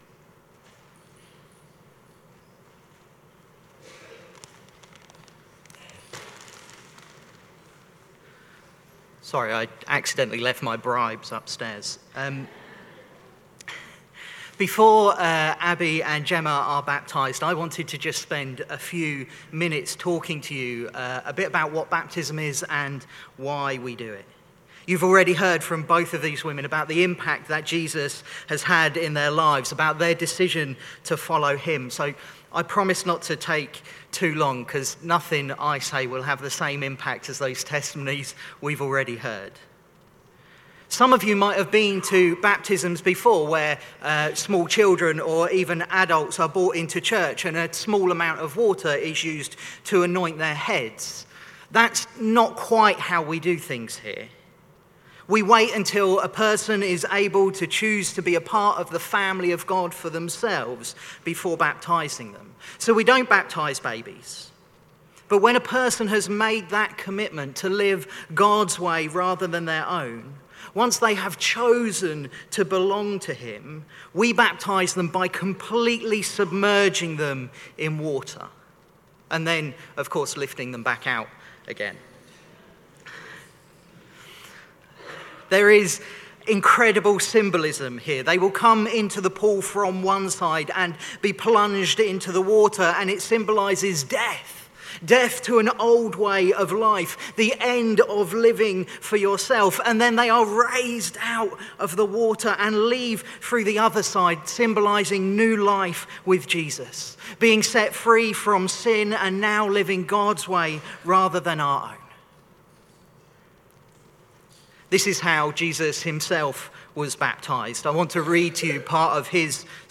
Baptism Service